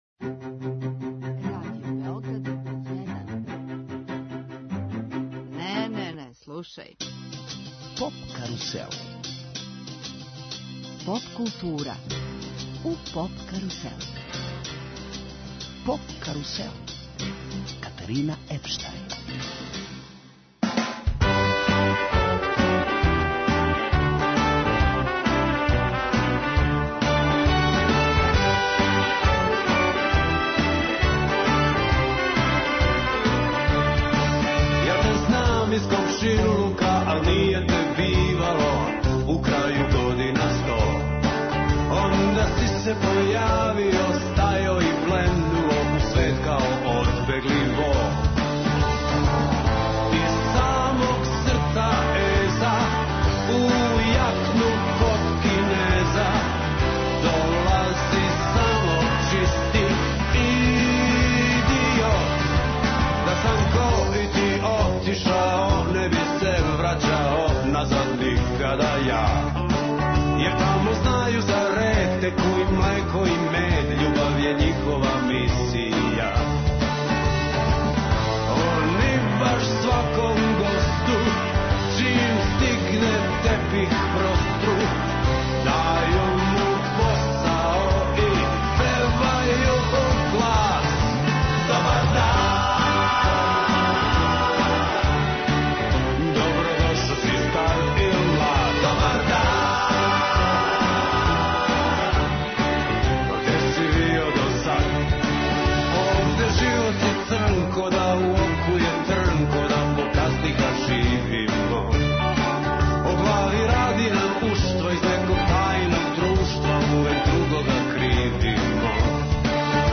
Гост емисије је музички уметник Никола Чутурило, поводом објављивања новог сингла 'Лице улице'.